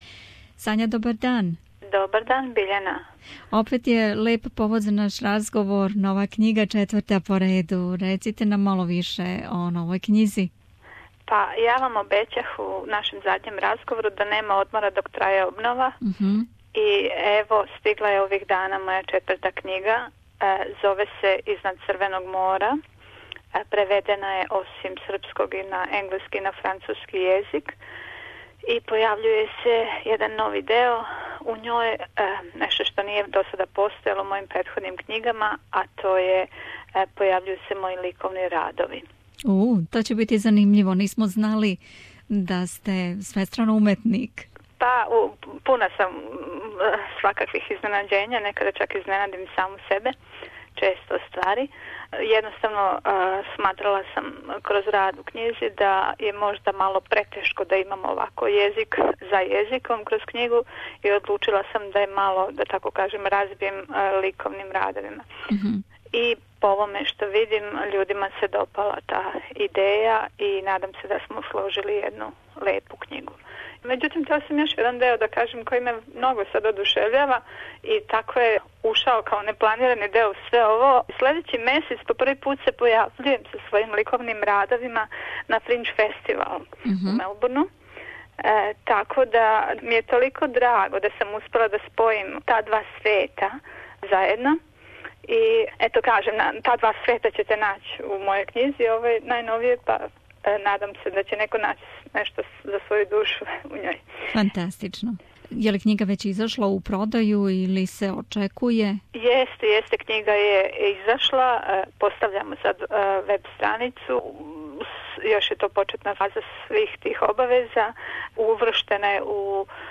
У разговору за наш програм